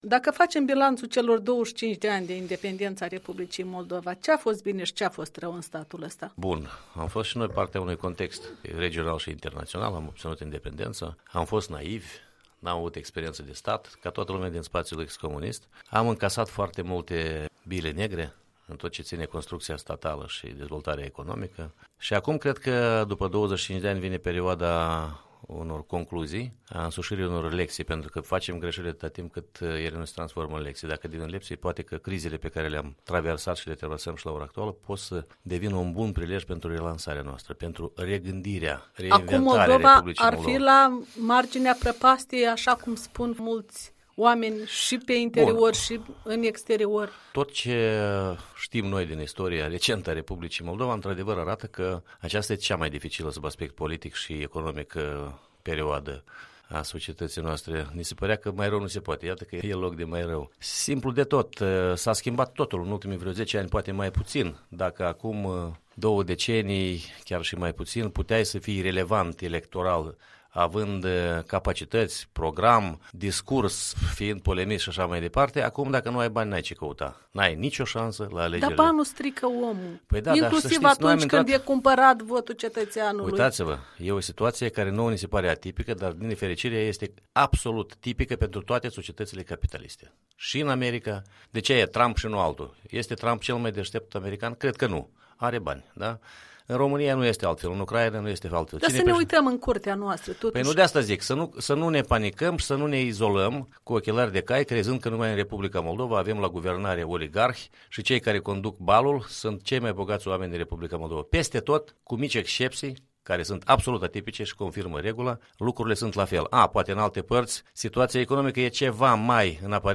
Interviu cu fostul lider al PPCD.